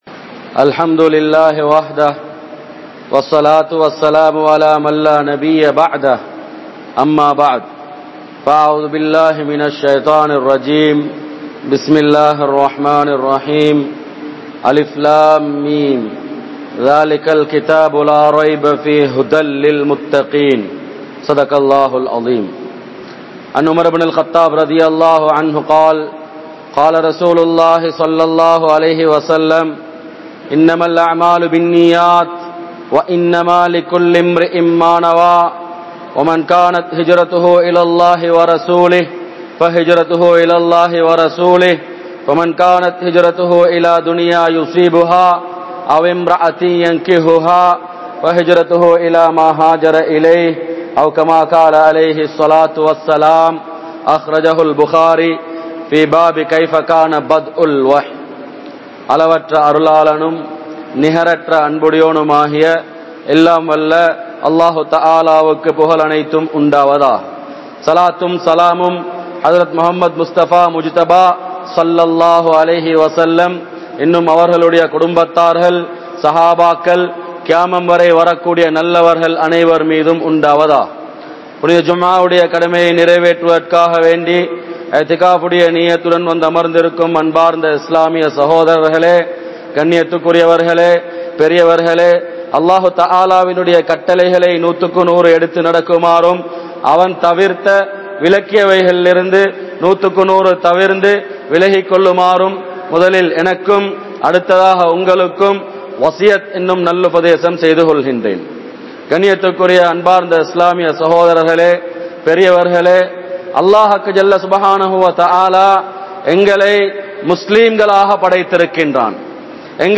Islaathai Marantha Muslimkal (இஸ்லாத்தை மறந்த முஸ்லிம்கள்) | Audio Bayans | All Ceylon Muslim Youth Community | Addalaichenai